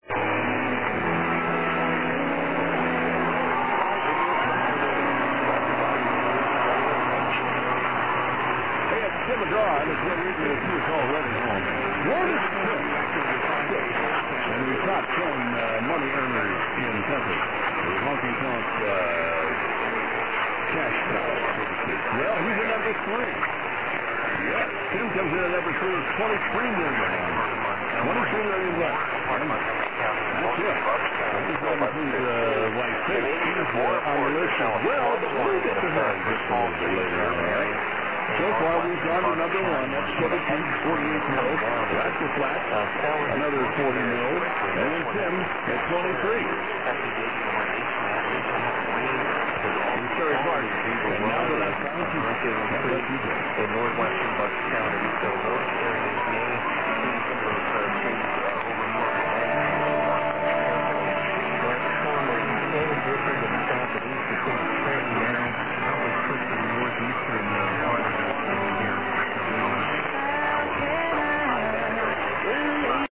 Here's some clips of the station recorded before midnight: